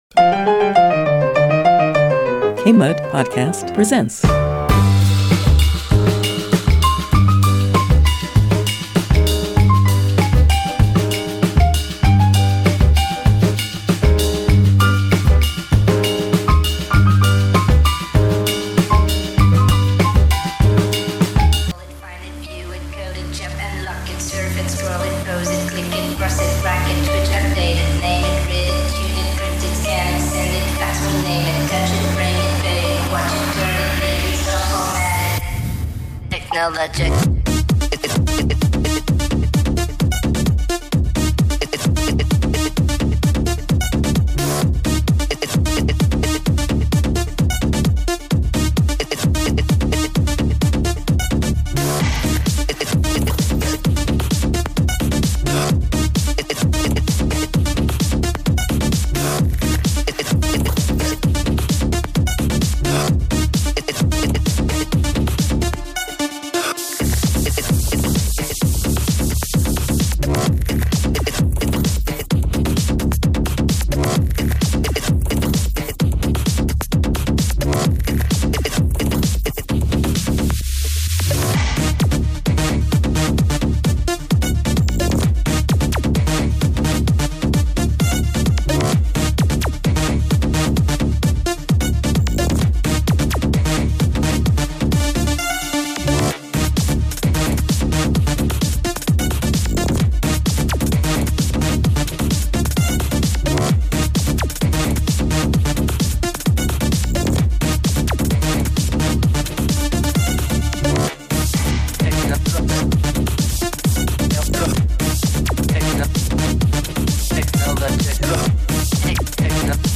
Along the way, they dig into real-world tech issues, cybersecurity concerns, and everyday device headaches, while taking live calls and listener questions.